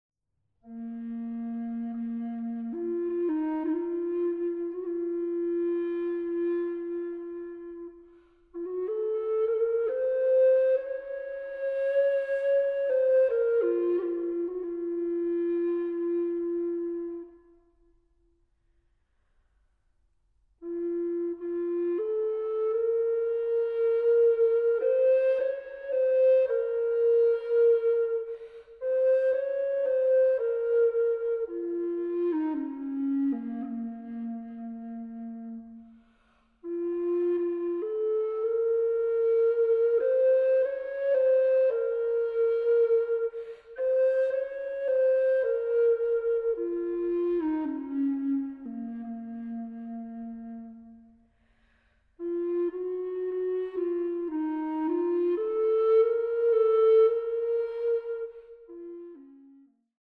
Recorder